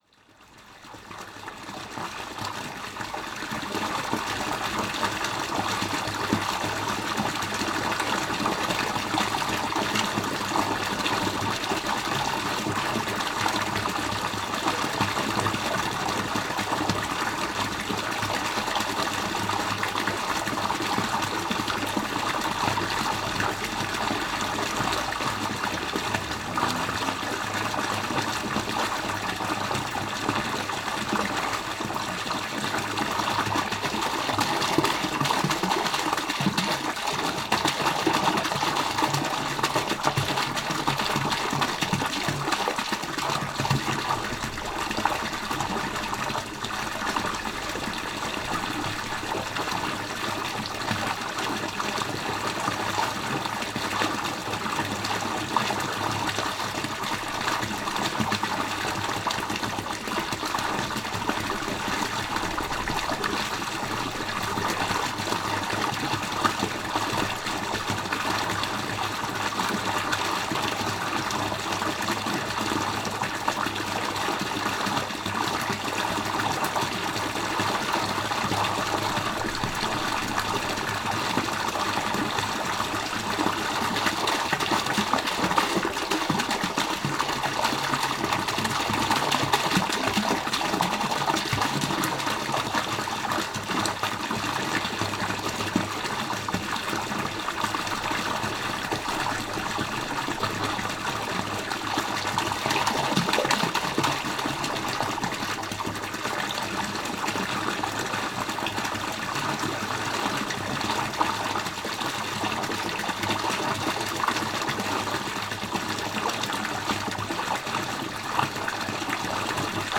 Paisagem sonora de escoamento de água de fonte / lavadouro em Vila Dum Santo, Côta a 10 Março 2016.